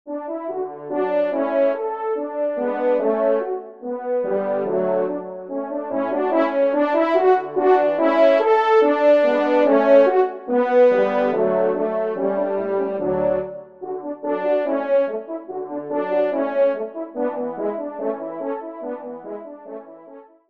24 compositions pour Trio de Cors ou de Trompes de chasse